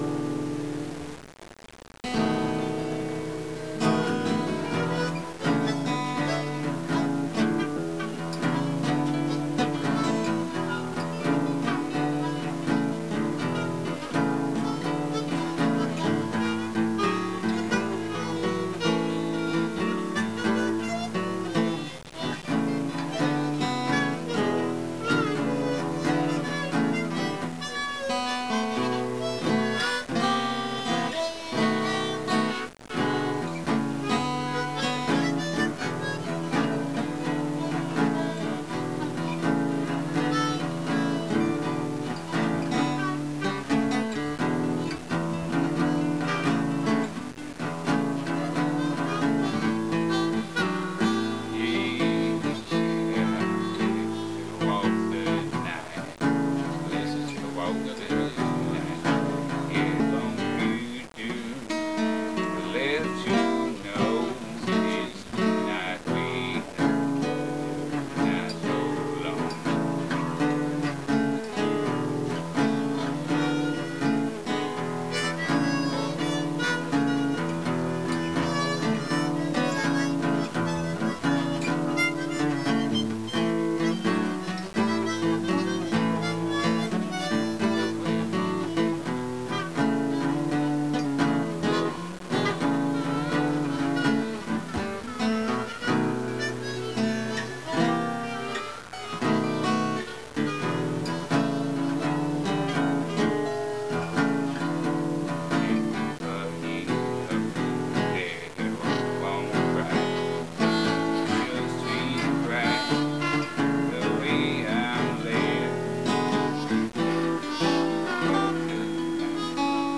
singing the blues (made up on the spot, take-two w/ one harmanica overdub